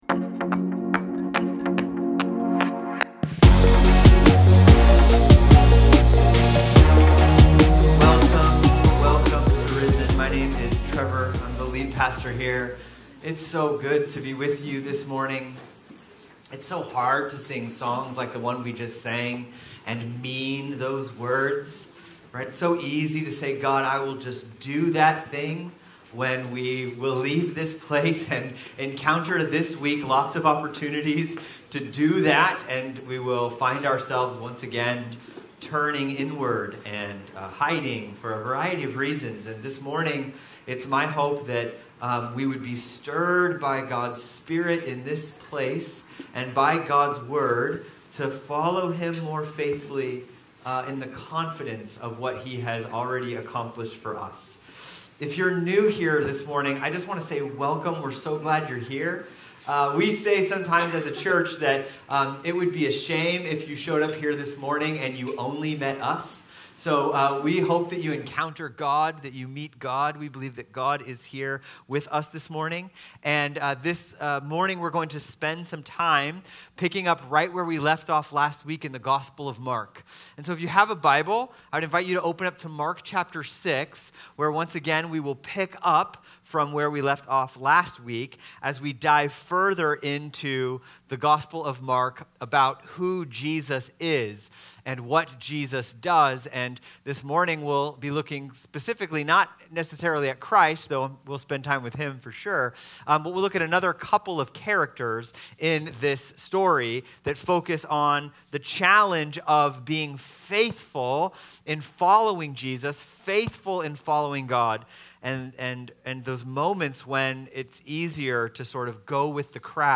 Sermons | RISEN CHURCH SANTA MONICA, INC